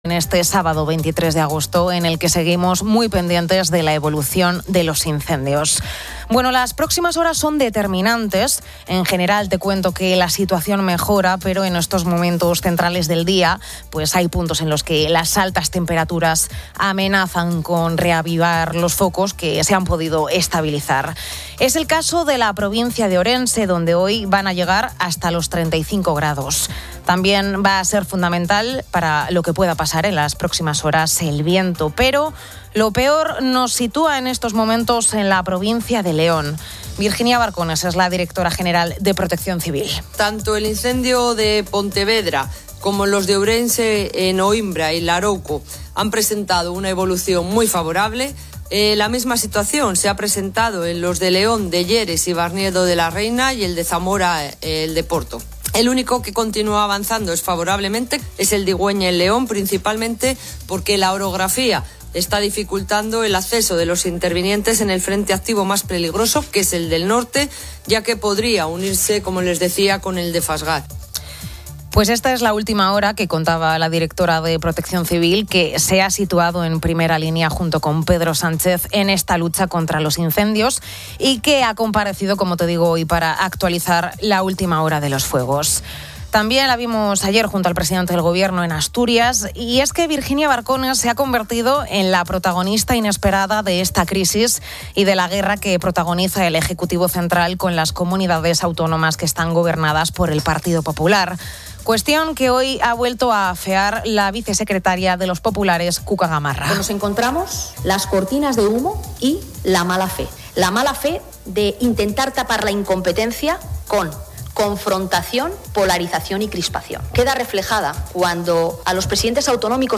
Testimonios de vecinos afectados reflejan un sentimiento de abandono.